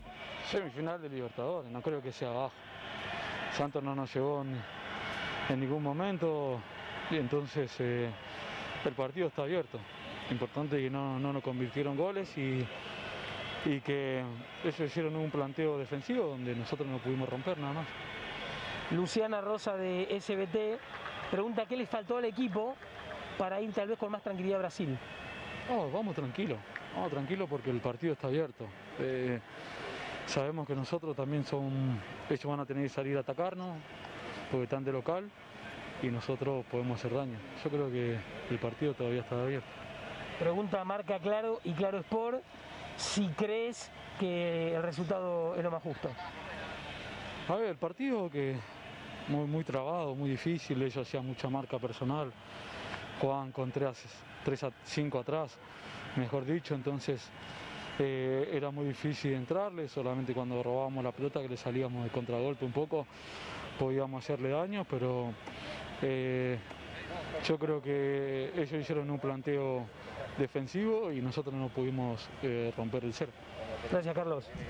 Carlos Tévez - Jugador Boca Juniors, Cortesía ESPN Argentina